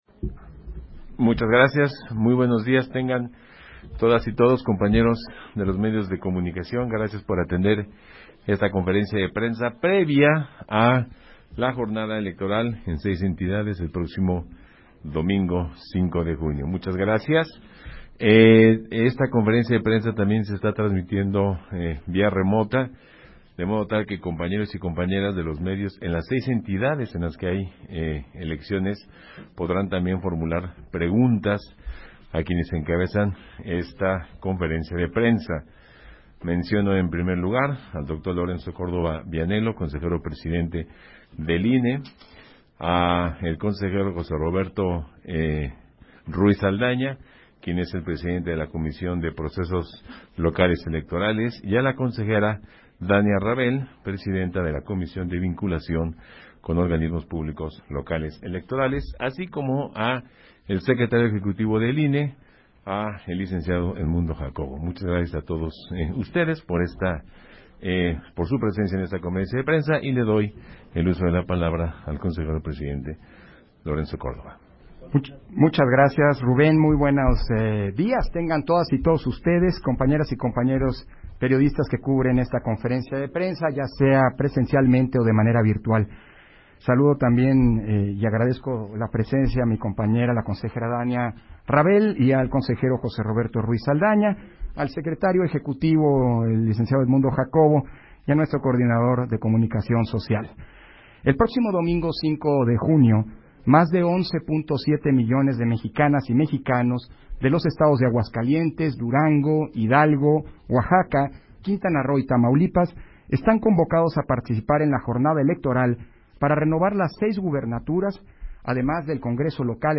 030622_AUDIO_CONFERENCIA-DE-PRENSA